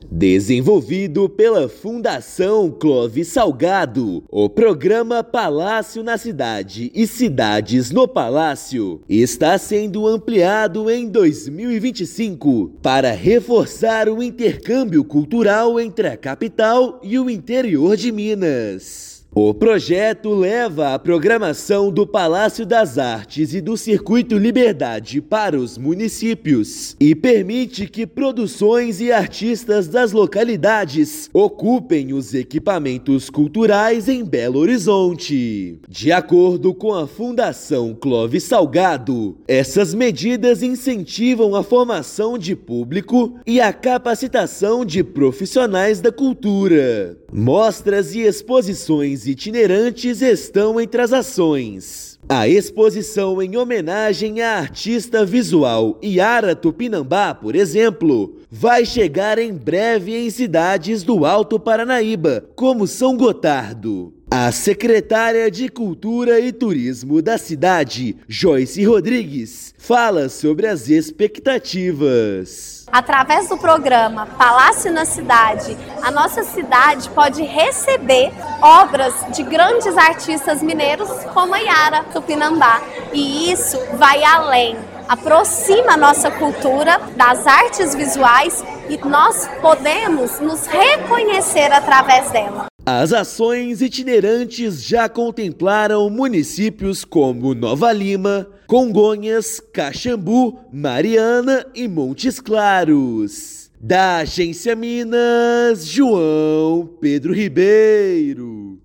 Iniciativa estimula intercâmbios e aproxima públicos, artistas e produções em torno da diversidade cultural do estado. Ouça matéria de rádio.